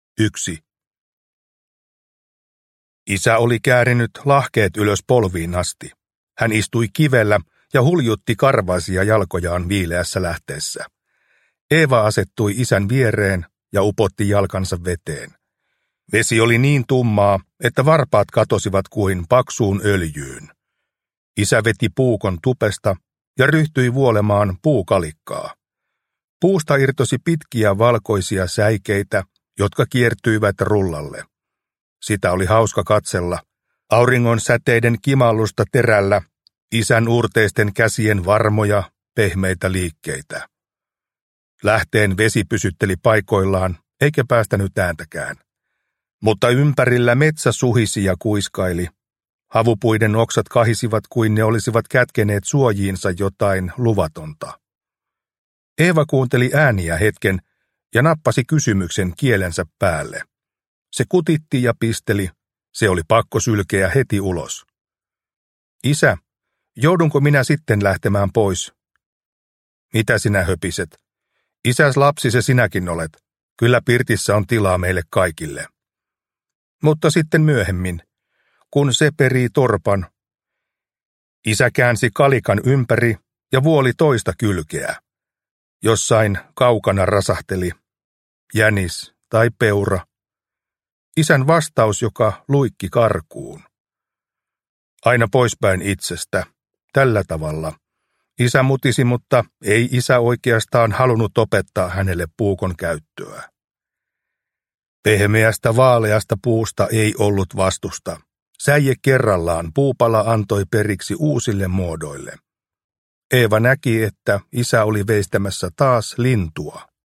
Språk: Finska